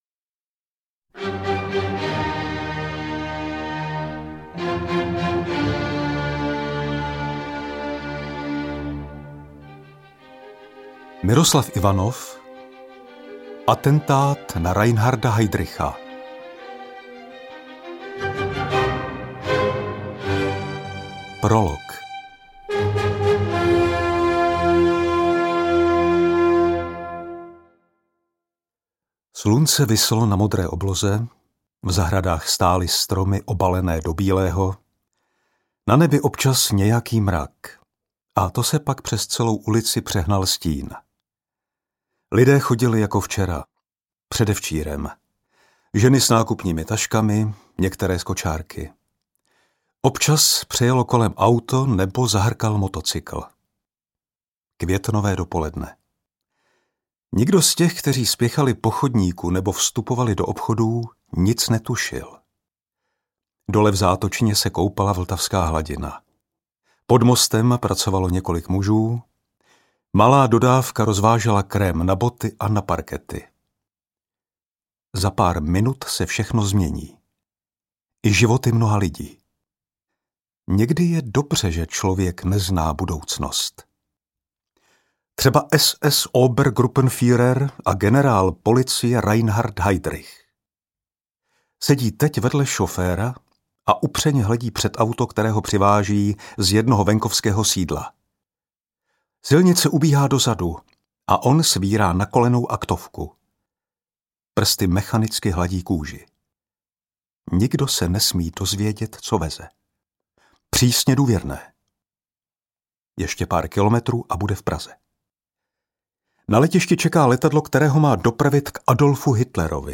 Interpret:  František Kreuzmann